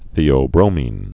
(thēō-brōmēn)